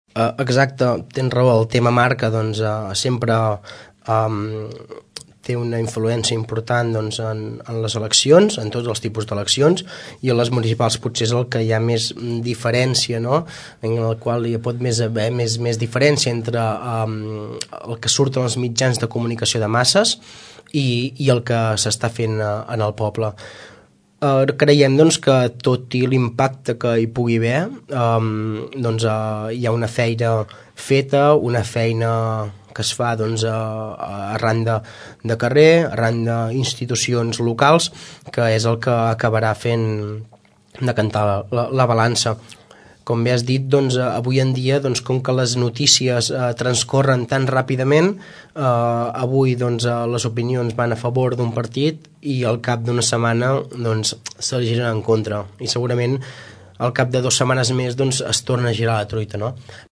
Ho explica el regidor Romaguera.